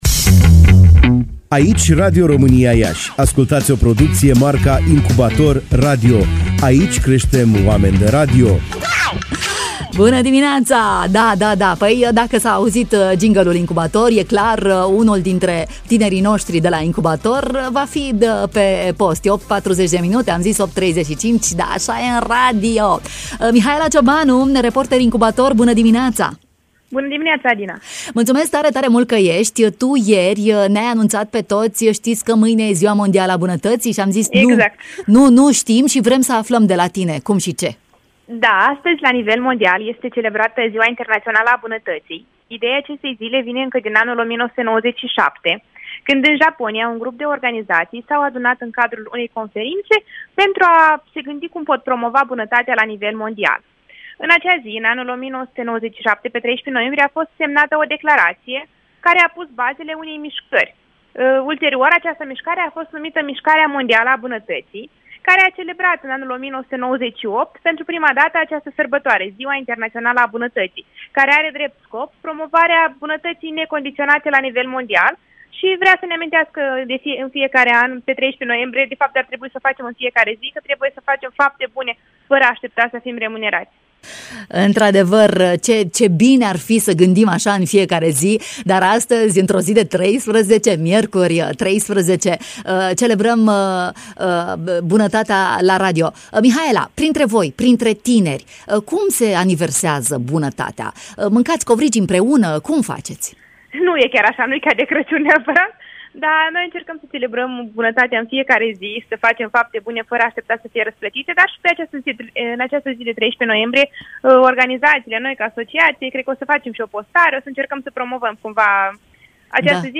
Reporter Incubator